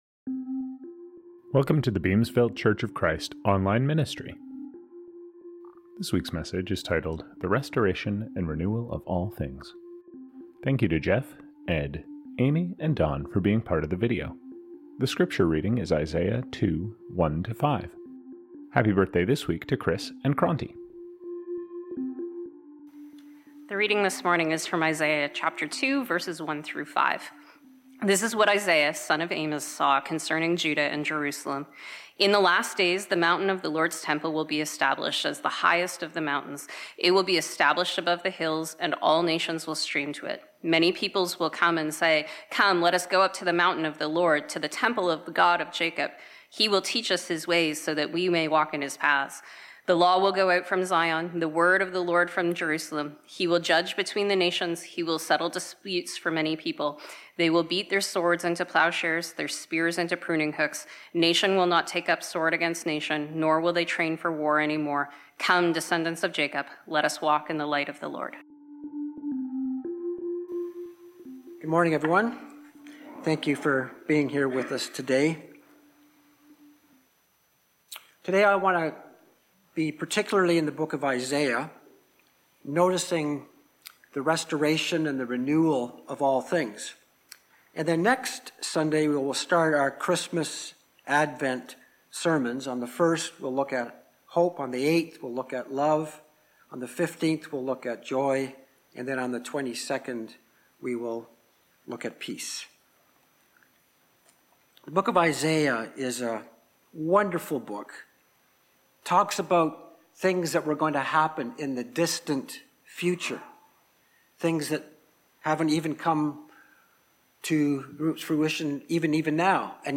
Songs from this service: